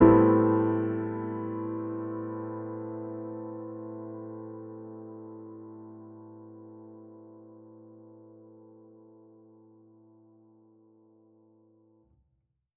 Index of /musicradar/jazz-keys-samples/Chord Hits/Acoustic Piano 1
JK_AcPiano1_Chord-Am9.wav